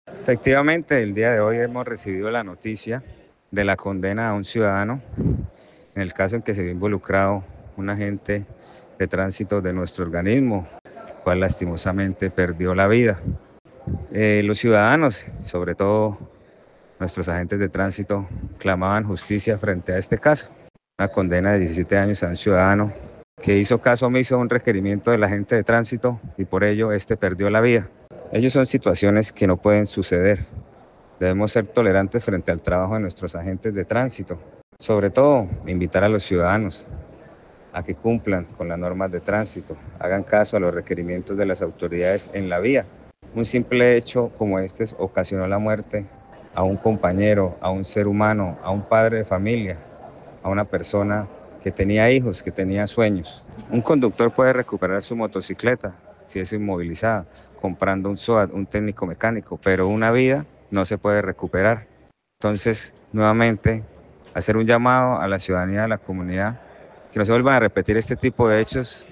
Jahir Castellanos, director de Tránsito de Floriodablanca